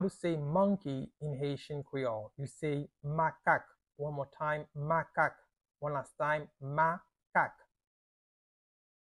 Listen to and watch “Makak” audio pronunciation in Haitian Creole by a native Haitian  in the video below:
Monkey-in-Haitian-Creole-Makak-pronunciation-by-a-Haitian-teacher.mp3